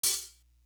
Real Mobb Do Hat.wav